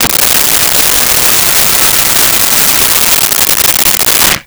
Blender On Puree
Blender on Puree.wav